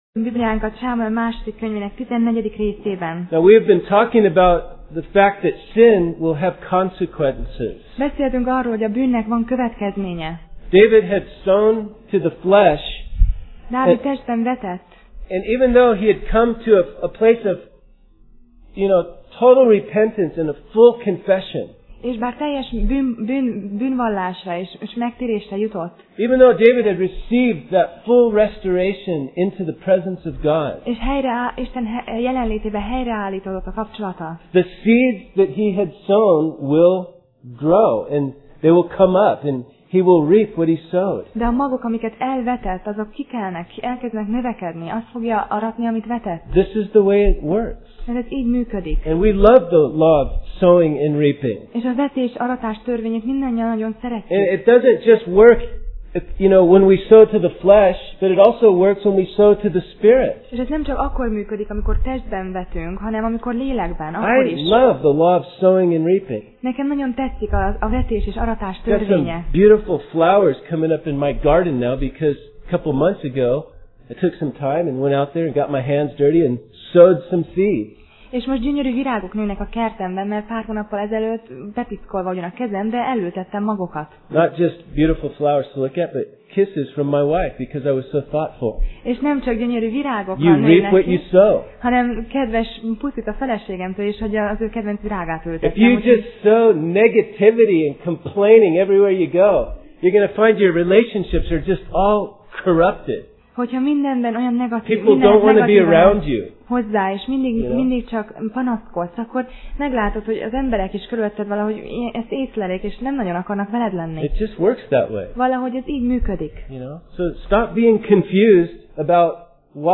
2Sámuel Passage: 2Sámuel (2Samuel) 13:37-14:24 Alkalom: Szerda Este